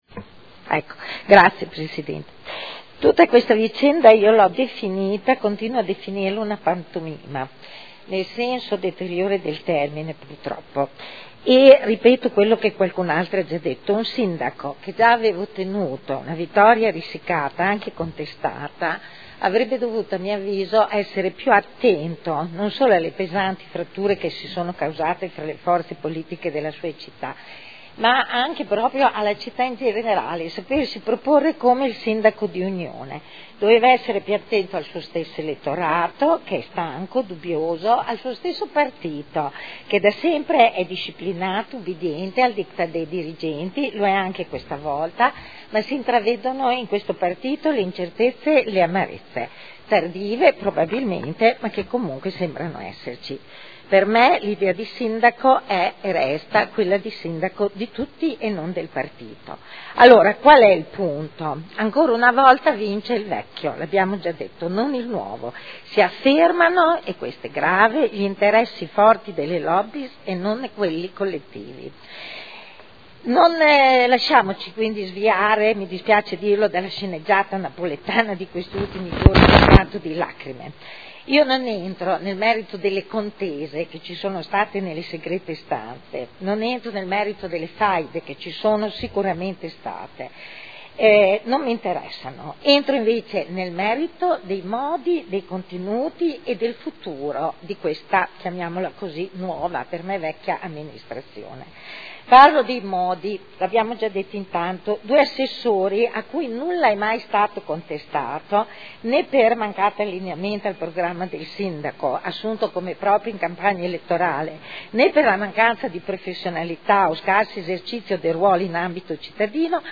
Seduta del 23/04/2012. Dibattito su comunicazione del Sindaco sulla composizione della Giunta.